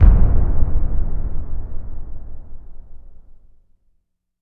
LC IMP SLAM 7B.WAV